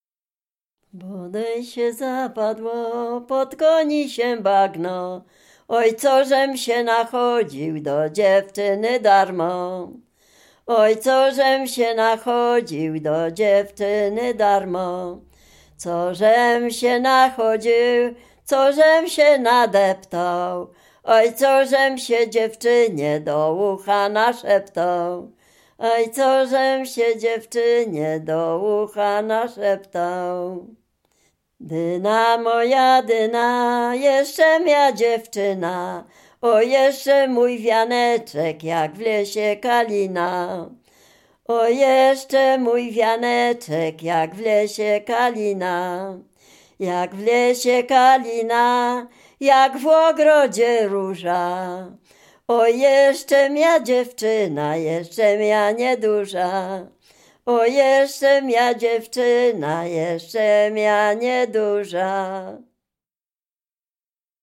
Dolny Śląsk, powiat bolesławiecki, gmina Osiecznica, wieś Przejęsław
Przyśpiewki